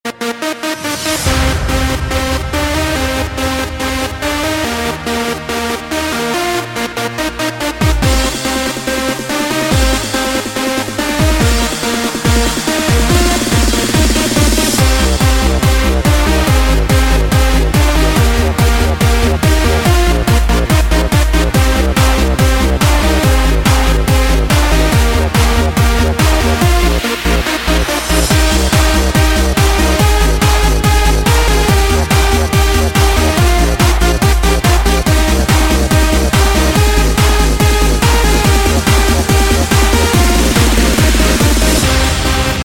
Thể loại nhạc chuông: Nhạc DJ